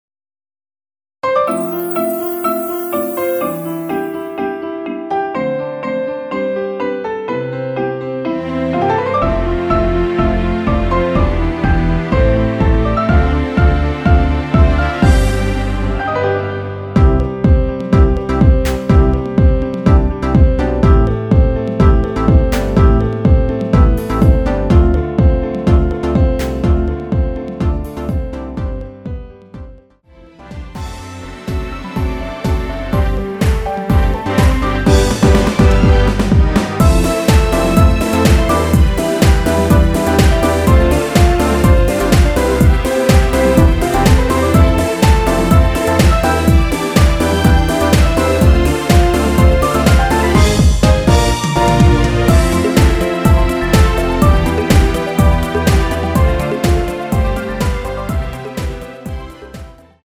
댄스버전 MR
엔딩이 페이드 아웃이라 엔딩을 만들어 놓았습니다.
앞부분30초, 뒷부분30초씩 편집해서 올려 드리고 있습니다.